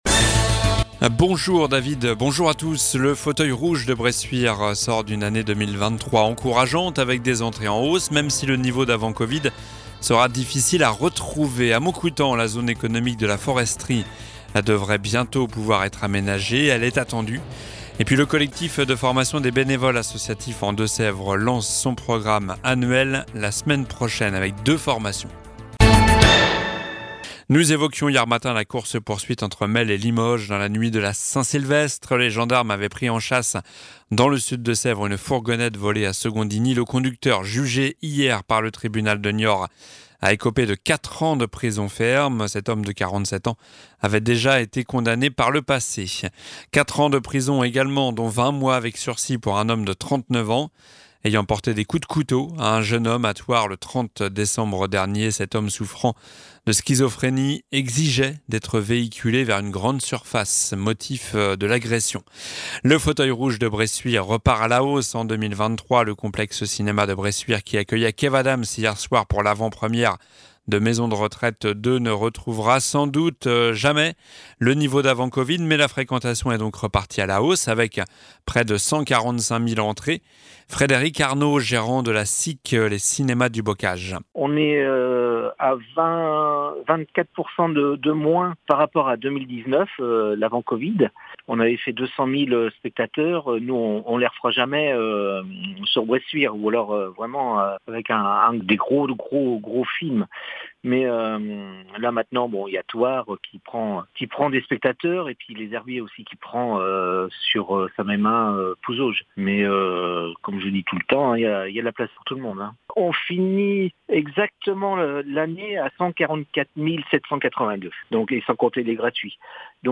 Journal du vendredi 05 janvier (midi)